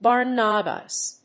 /ˈbɑrnʌbʌs(米国英語), ˈbɑ:rnʌbʌs(英国英語)/